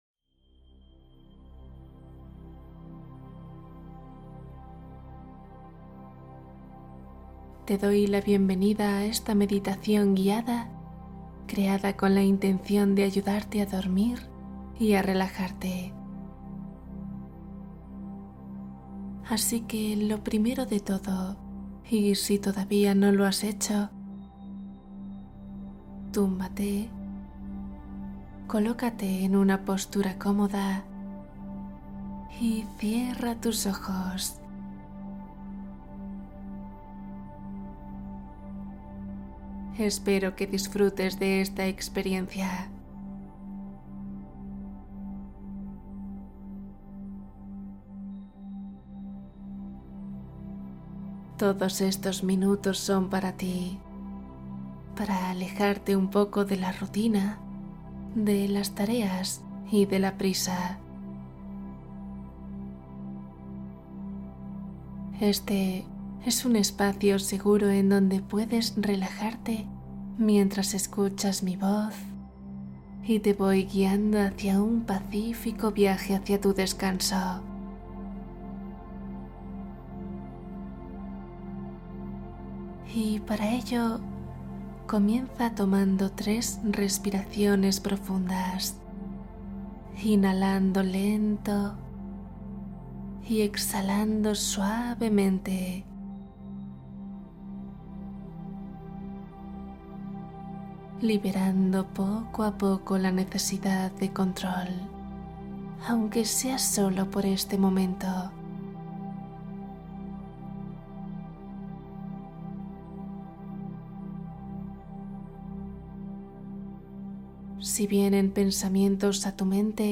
Cuento para dormir ❤ Relajación profunda para descansar